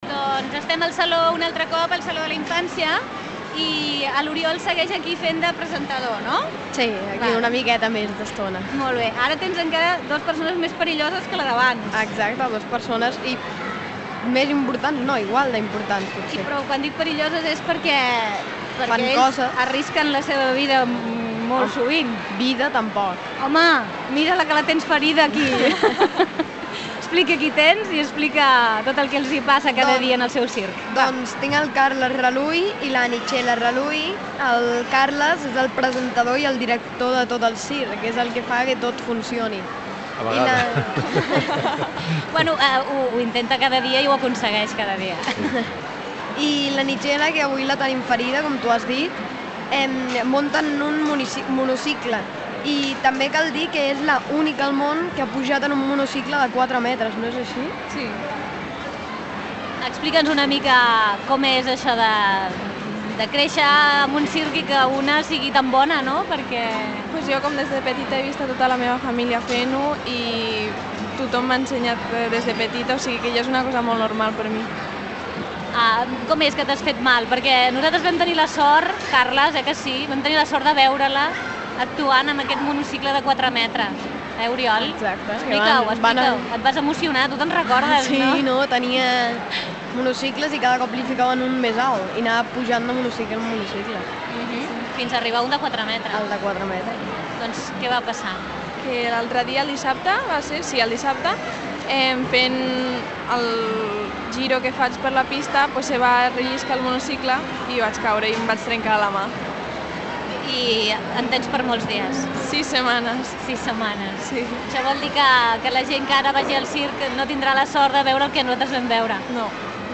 Emissió des del Saló de la Infància del programa infantil-jovenil amb una entrevista a dos membres del Circ Raluy
Infantil-juvenil